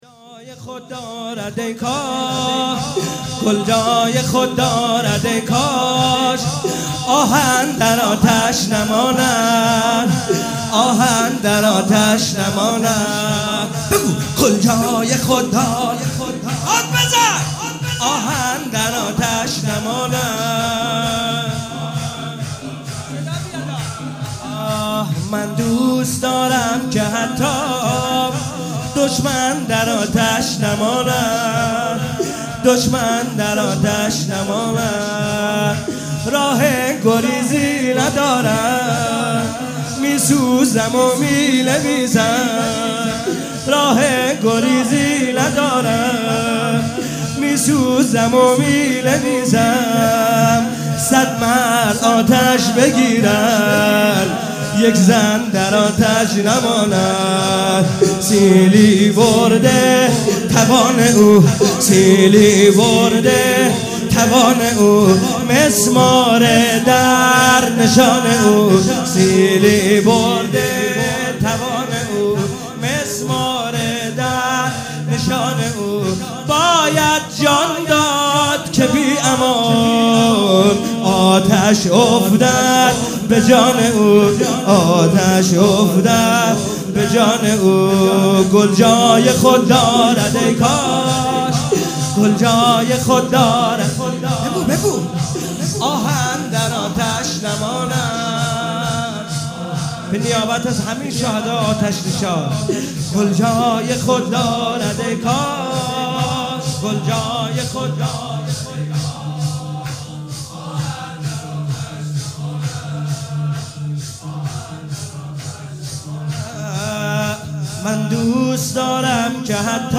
[شور]